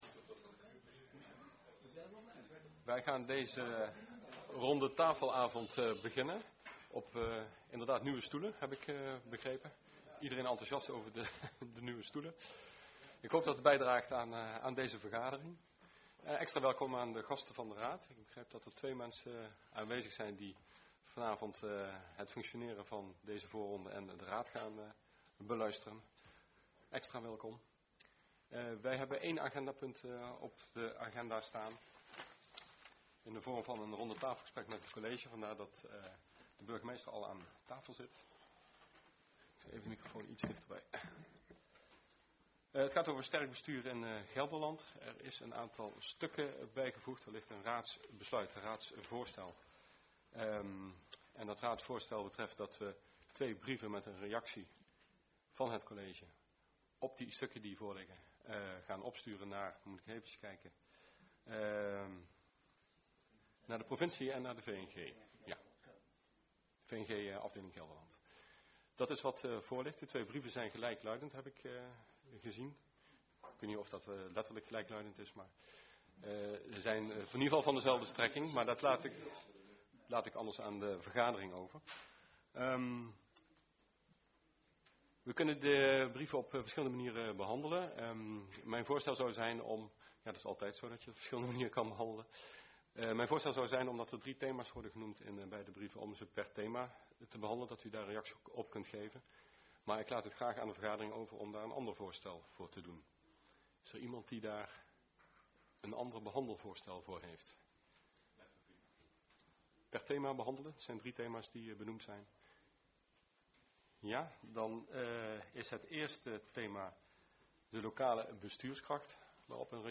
Commissiekamer, gemeentehuis Elst